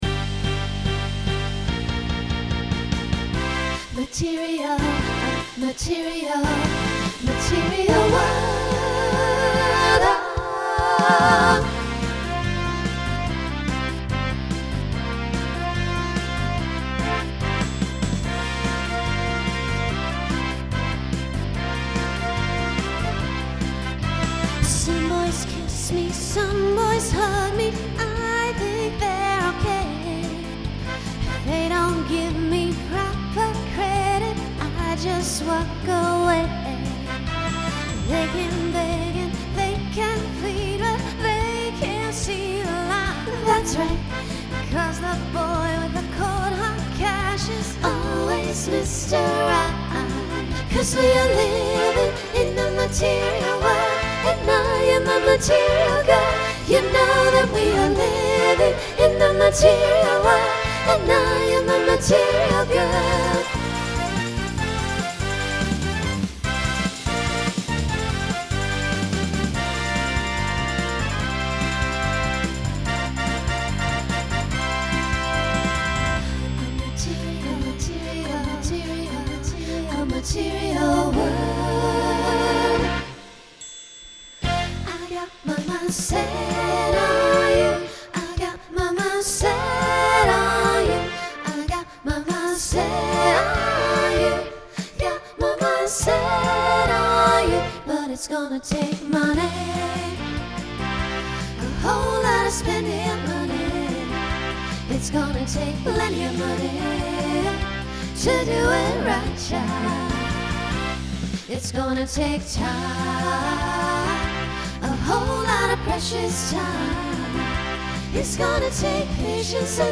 Genre Pop/Dance Instrumental combo
Opener Voicing SSA